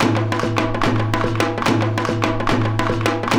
KOREA PERC 5.wav